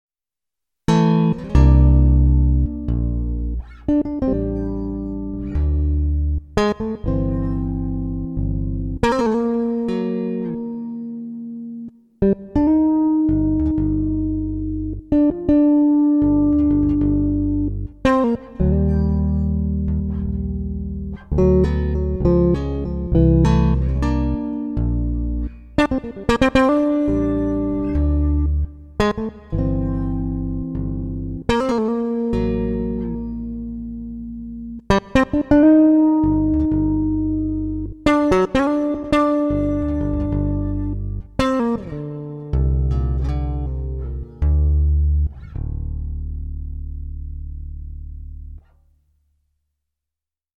All of the samples were DI'd (direct injected) for sampling.